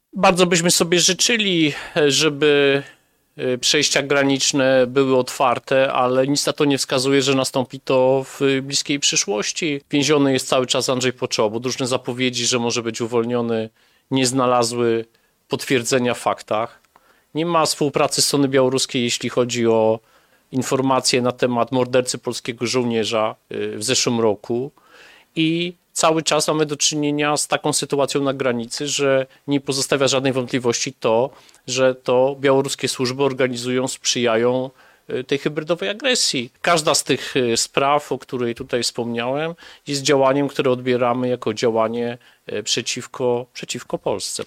Nie ma szans na otwarcie przejść granicznych z Białorusią w województwie podlaskim. Mówił w poniedziałek (13.01) na konferencji prasowej zorganizowanej w Podlaskim Urzędzie Wojewódzkim minister MSWiA Tomasz Siemoniak.